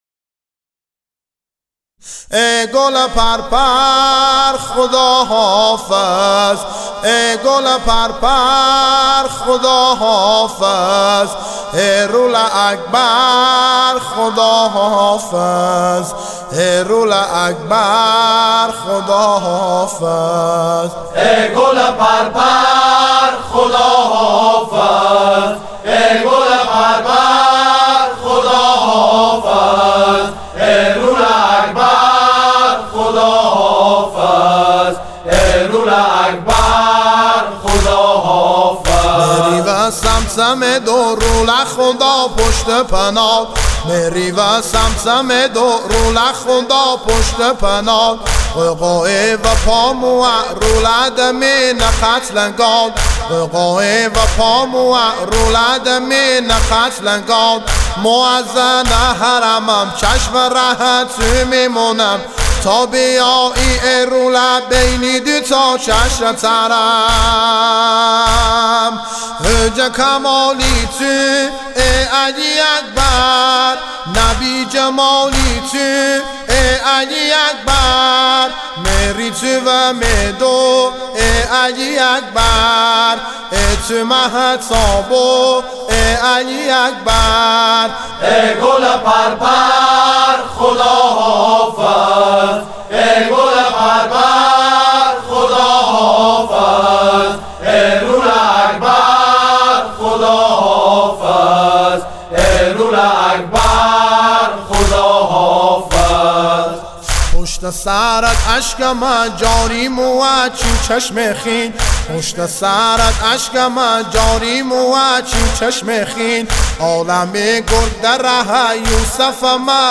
مداحی و نوحه لری